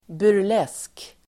Ladda ner uttalet